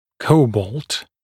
[‘kəubɔːlt] [-bɔlt][‘коубо:лт] [-болт]кобальт